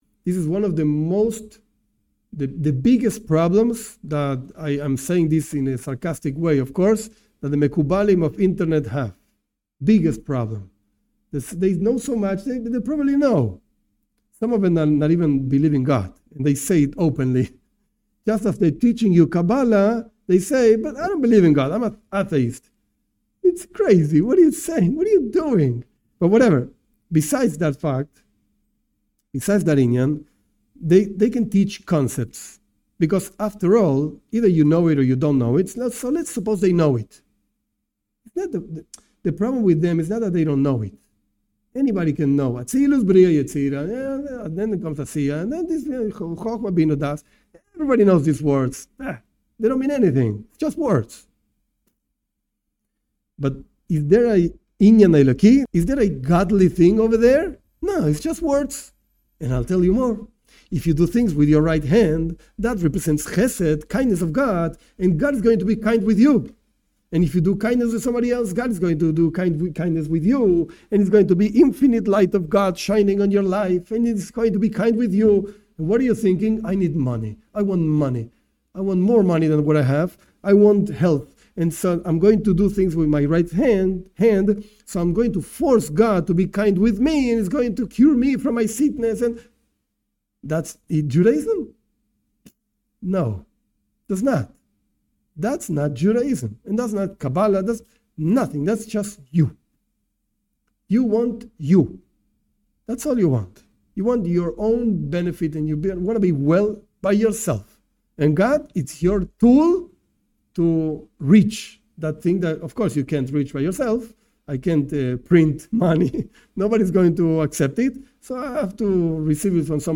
This brief part of a class explains one of why the Kabbalah taught online is not true (on most of the cases). There a popular saying: those who know, don't talk, those who talk, don't know...